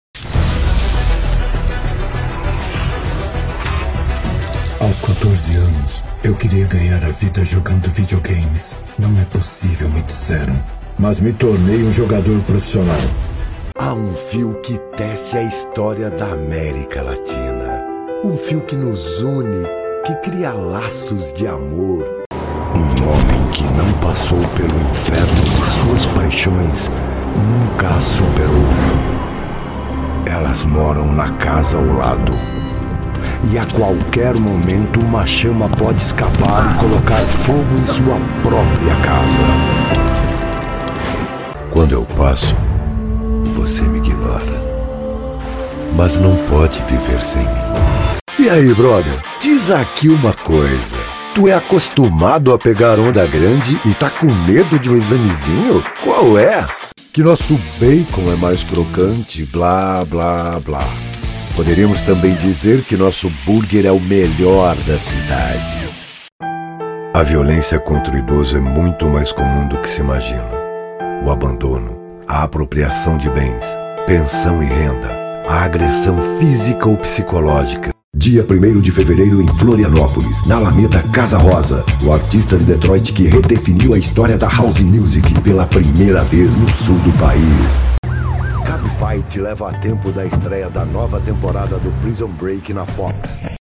Brazil
aspirational, authoritative, character, dramatic, elegant, informative, intimate, modern, seductive
45 - Above
My demo reels